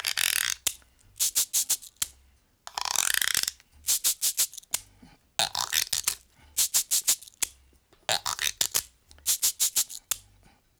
88-PERC11.wav